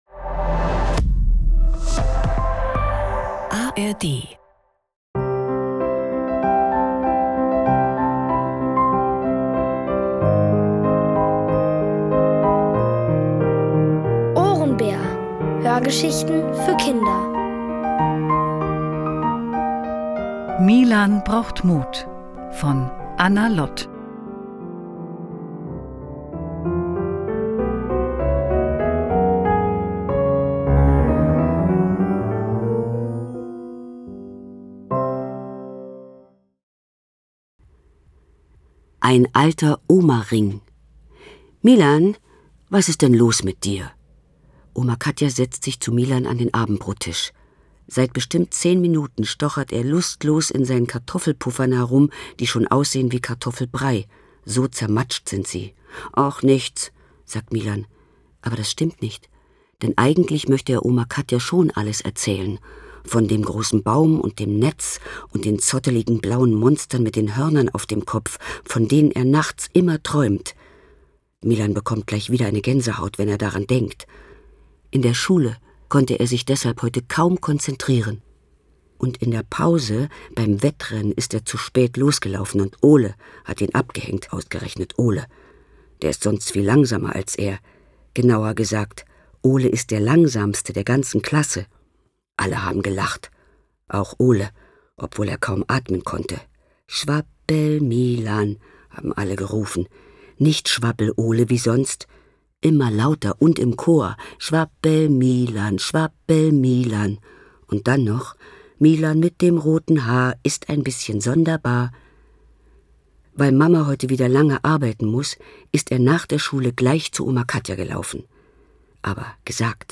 Es liest: Petra Kelling.